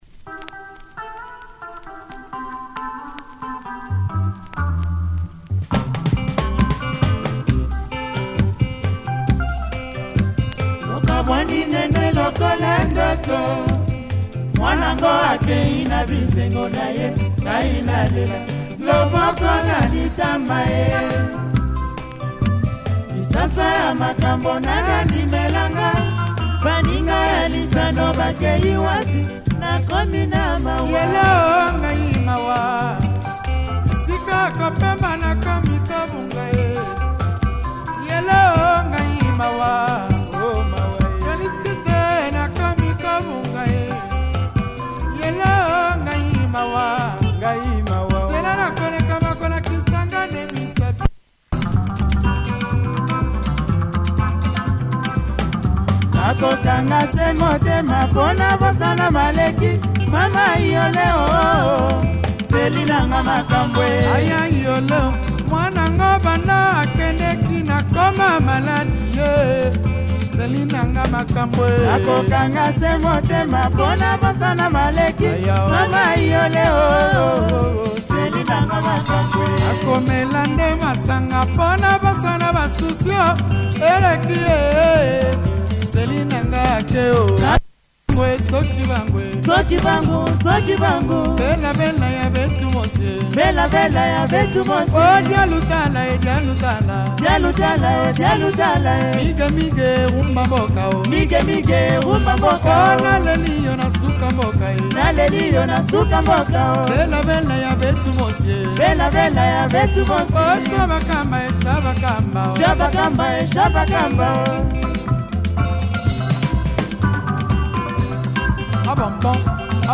Lingala